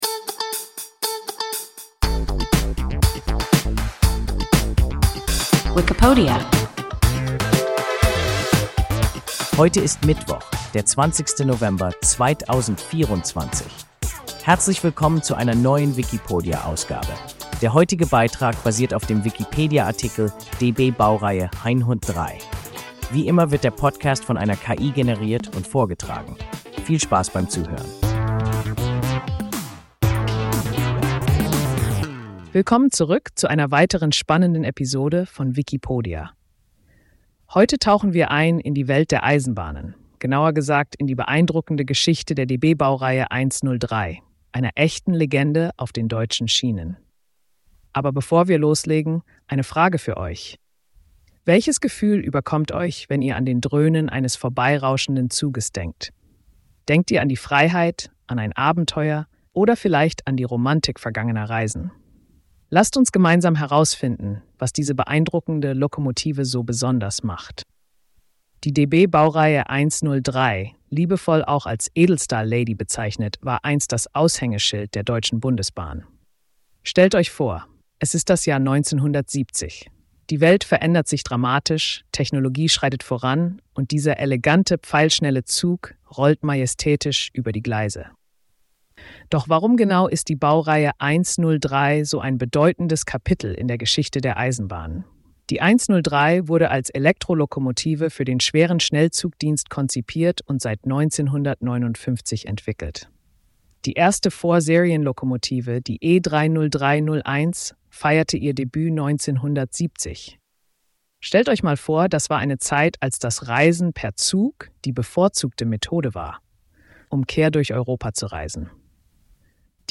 DB-Baureihe 103 – WIKIPODIA – ein KI Podcast